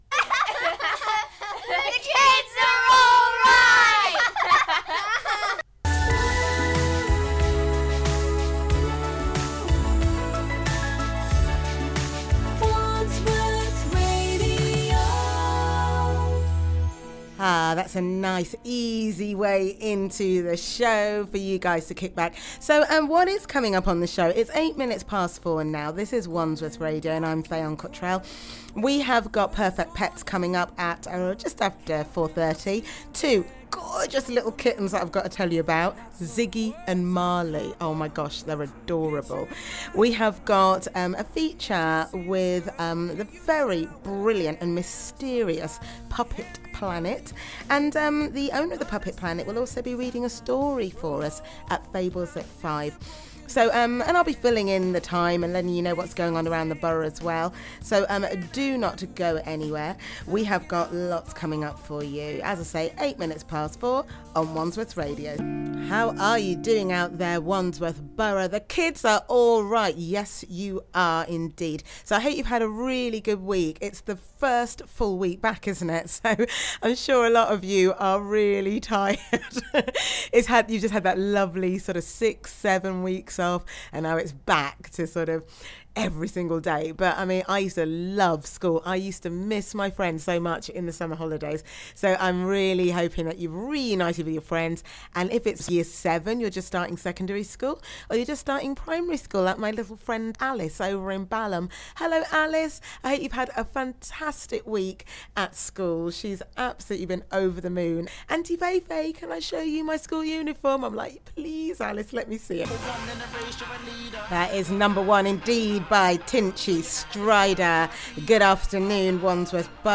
Interview with Puppet Planet on Wandsworth Radio